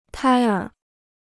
胎儿 (tāi ér) Dictionnaire chinois gratuit